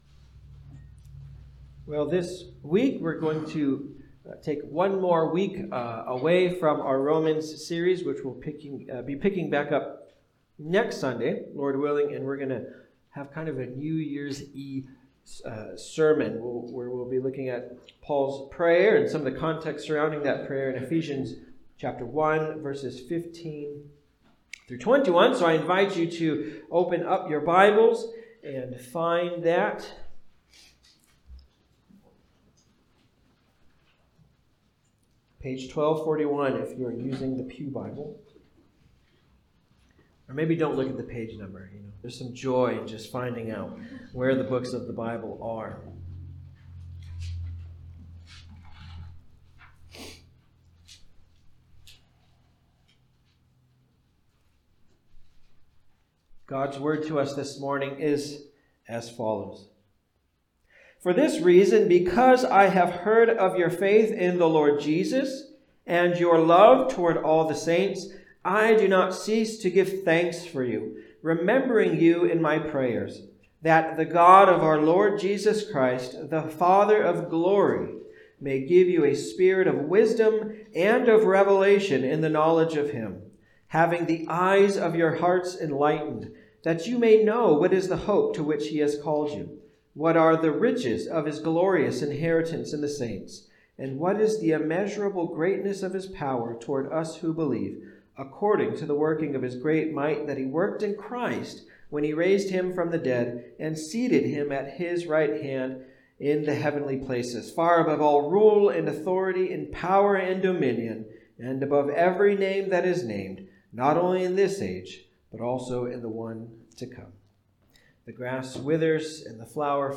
Ephesians 1:15-21 Service Type: Sunday Service « The Crisis of Christmas What Time Is It?